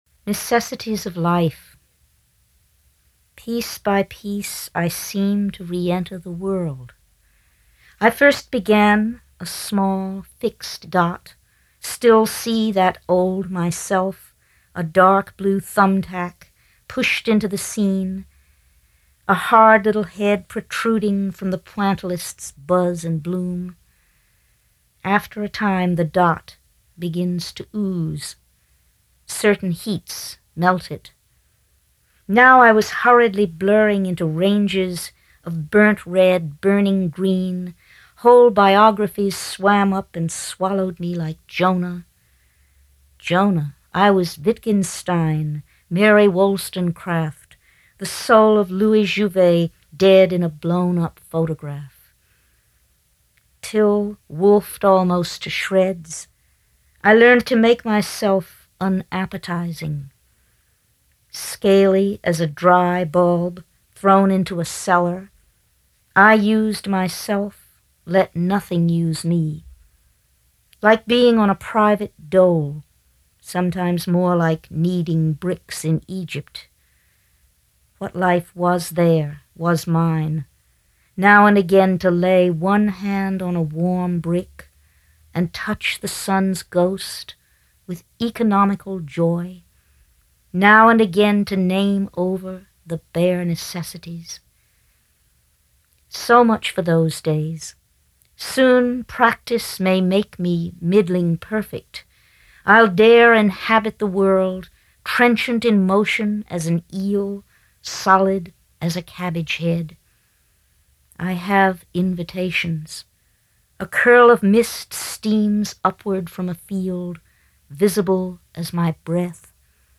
Audio files here are from Rich’s recordings for the University of Cincinnati’s Elliston Project; from the 92nd Street Y in New York City; from PennSound; and from the Voice of the Poet series edited by J.D. McClatchy in 2002 for Random House Audio.